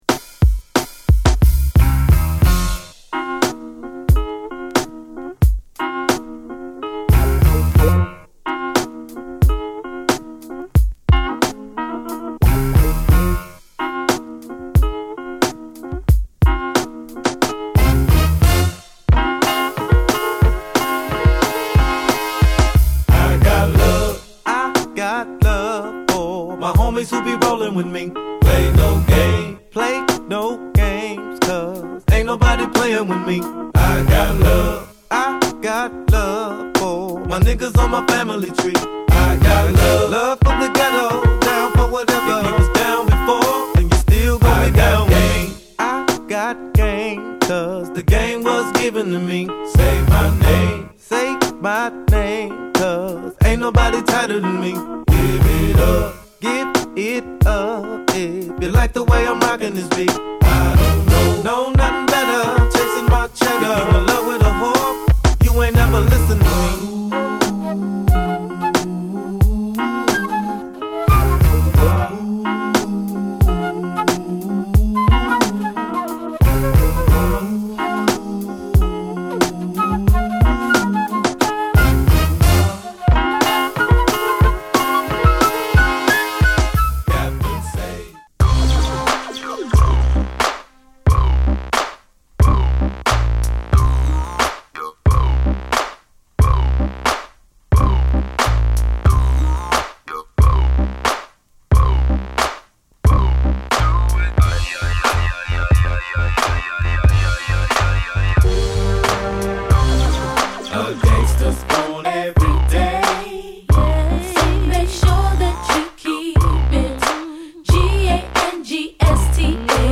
本作も当然G-Funkなサウンドプロダクションが並び
レイドバックしたメロウなG-Funkビートで唯一無二のボーカルを聴かせる名作！